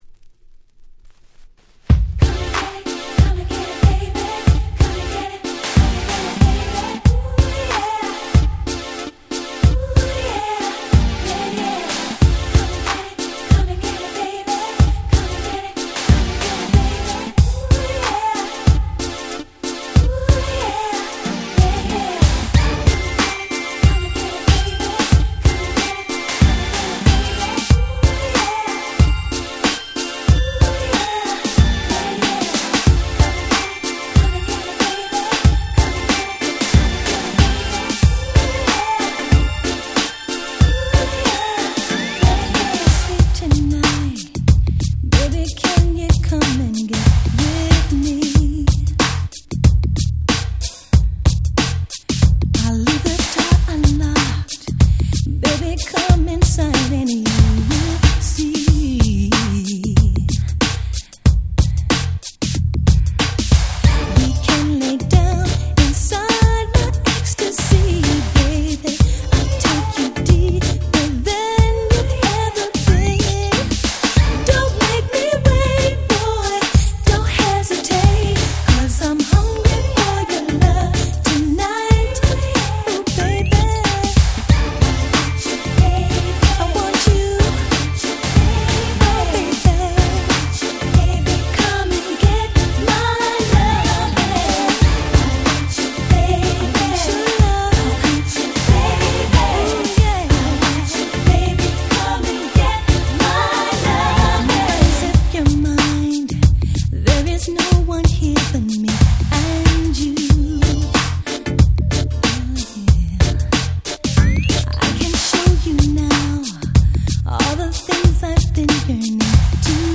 92' Nice R&B / Slow Jam !!
90's スロウジャム